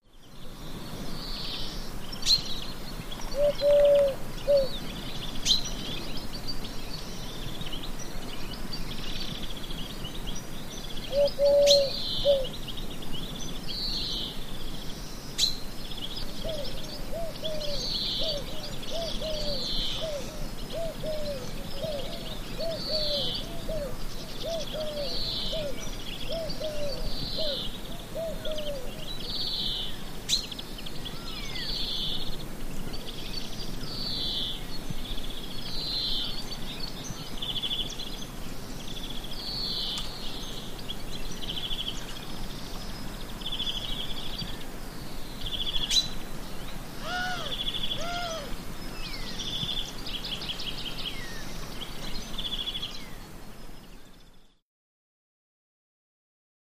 Israel Biblical Reserve, Early Morning Exterior Bird Ambience Near Pond